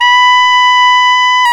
Index of /90_sSampleCDs/Roland LCDP07 Super Sax/SAX_Tenor V-sw/SAX_Tenor _ 2way
SAX TENORM14.wav